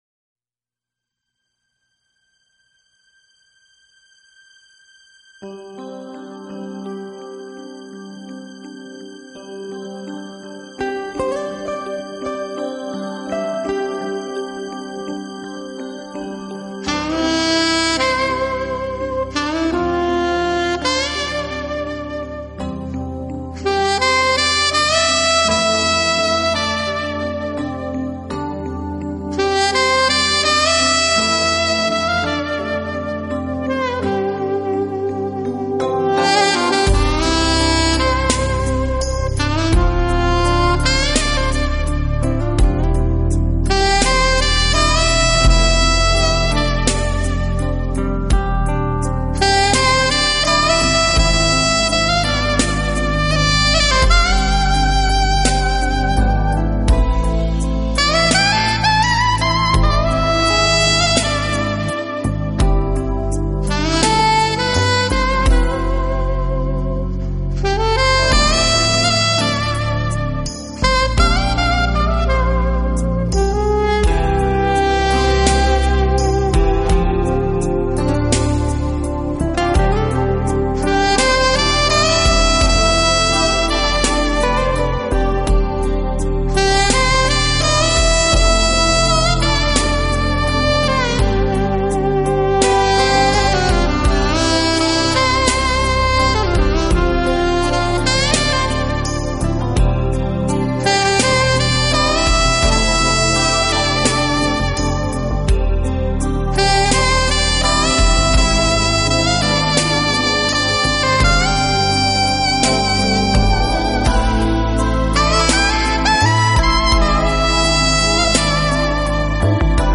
Genre：Smooth Jazz